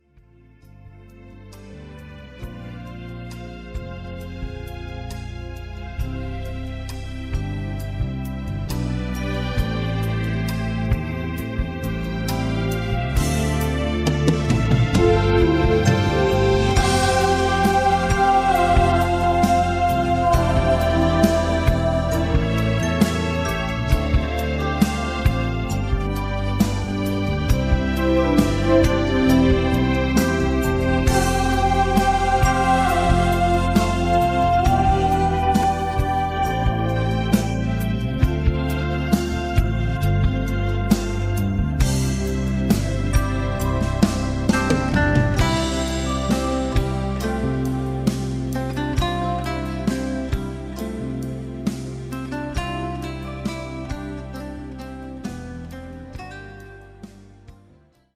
음정 -1키 4:18
장르 가요 구분 Voice MR
보이스 MR은 가이드 보컬이 포함되어 있어 유용합니다.